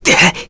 Arch-Vox_Damage_kr_02.wav